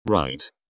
Tags: voice control robot